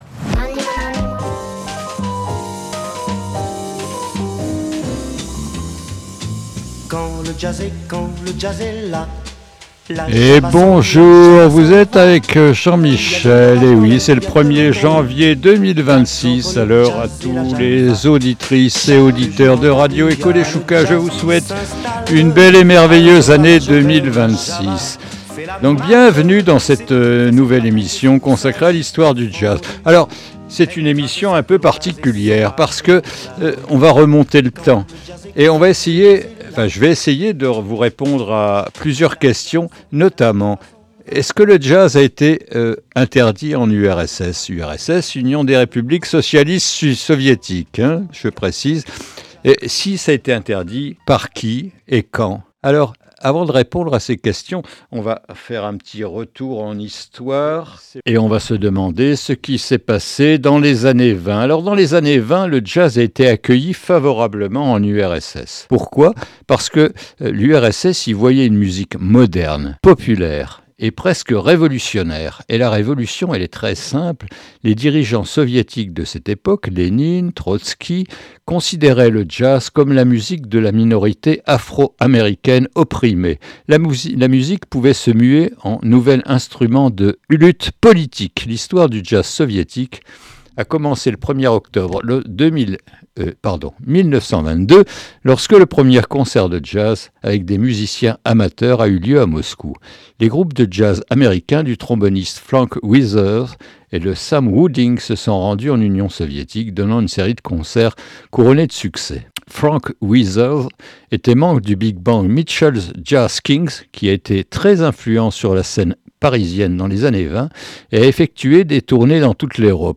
Quand le jazz est là est une nouvelle émission consacrée à l’histoire du jazz.